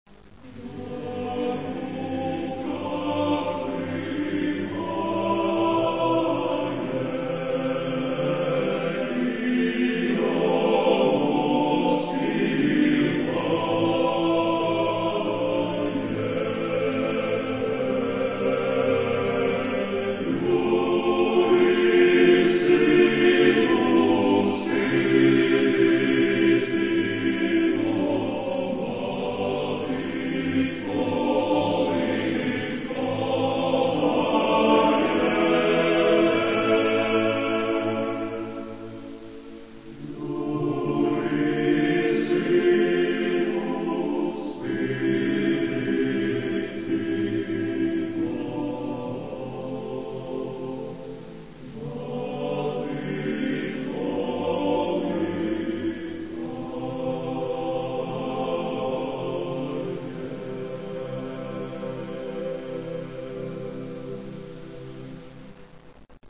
Каталог -> Классическая -> Хоровое искусство
Здесь представлены рождественские песнопения, колядки.